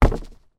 woodFootstep01.wav